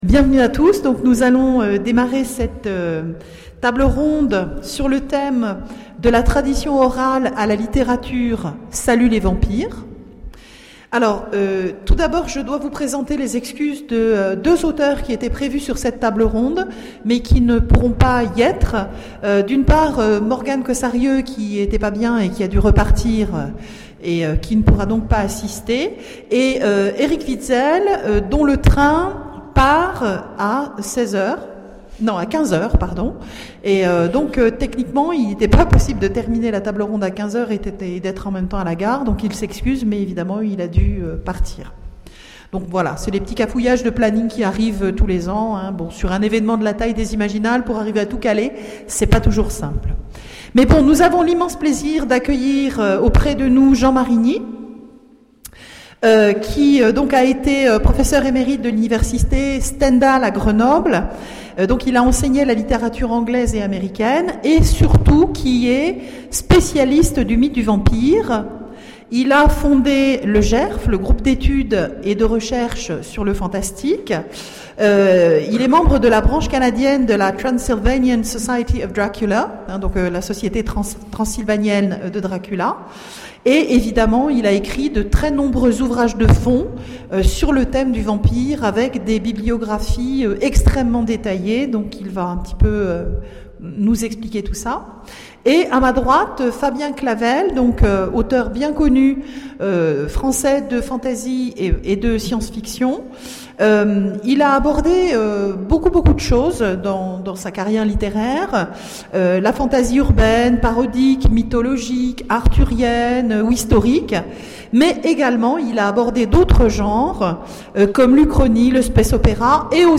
Imaginales 2013 : Conférence Des classiques du XIXe siècle aux récits contemporains...